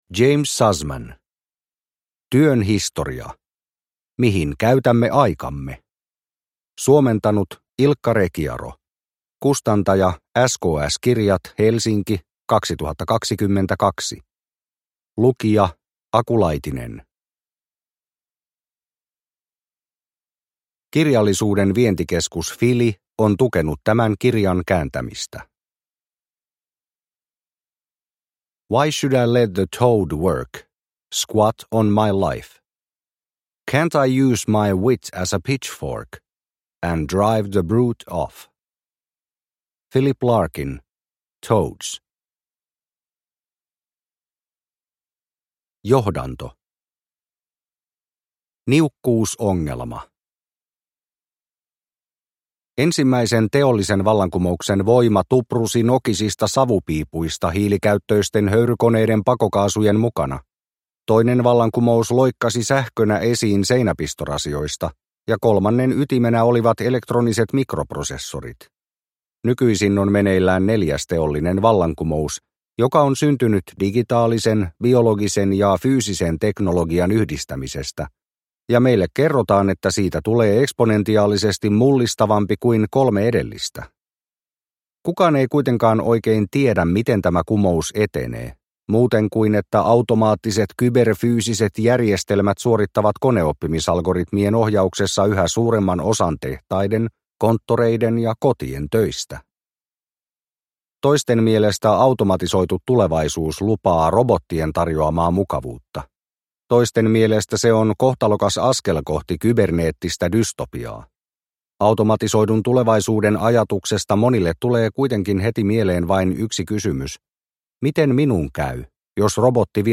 Työn historia – Ljudbok – Laddas ner